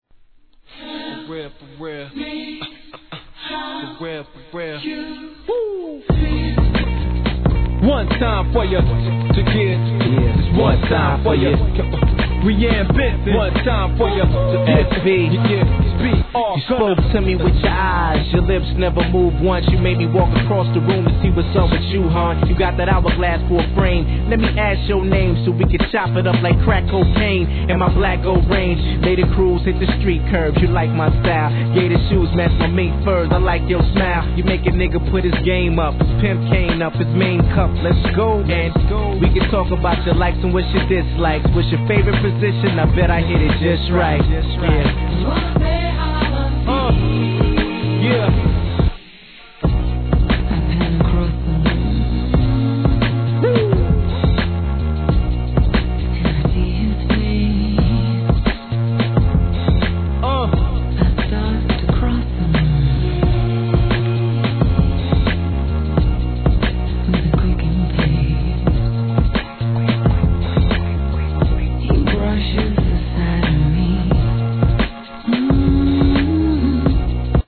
HIP HOP/R&B
想像通りのソウルフル・ナンバーです♪